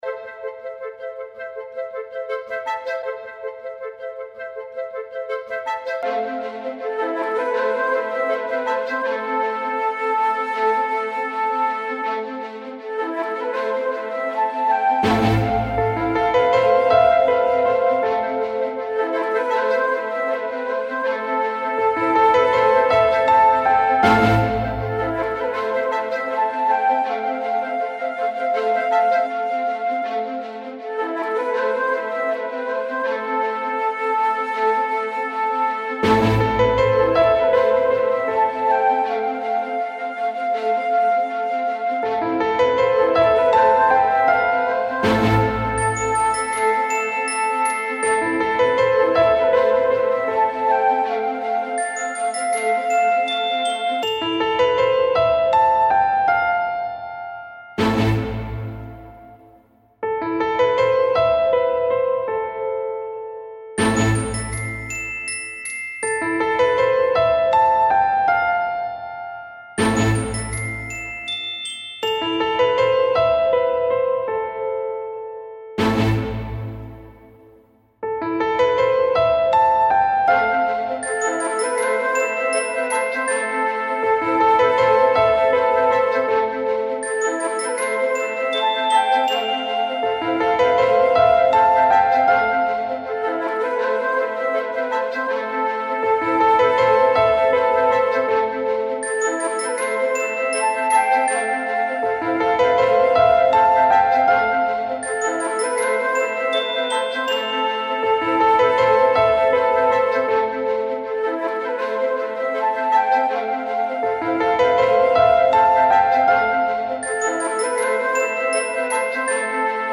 Žánr: Electro/Dance
Elektronika se snoubí s klasickou hudbou